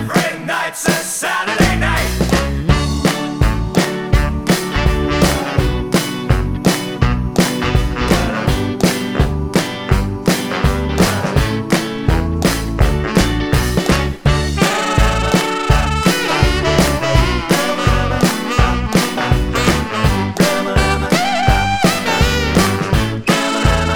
No End Solo Rock 'n' Roll 2:42 Buy £1.50